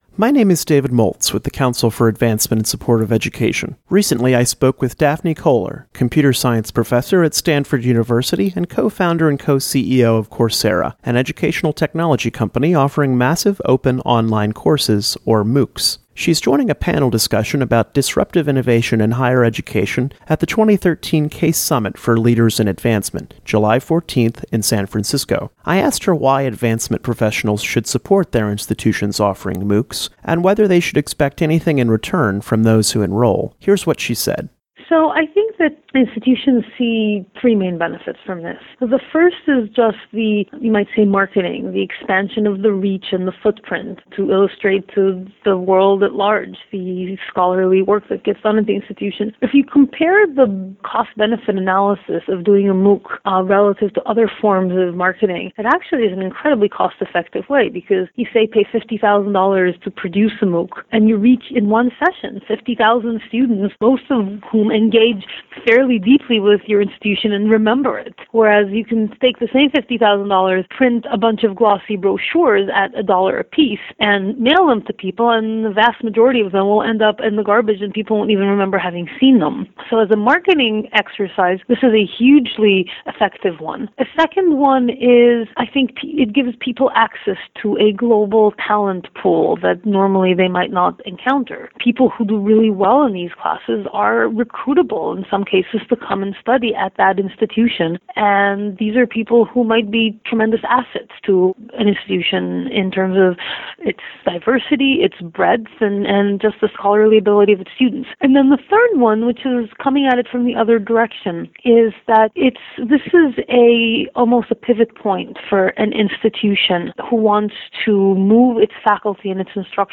Massive open online courses (MOOCs) present a great marketing opportunity for educational institutions, says Daphne Koller, co-founder of Coursera in a recent interview with CASE.